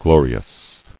[glo·ri·ous]